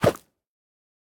minecraft / sounds / mob / goat / jump2.ogg
jump2.ogg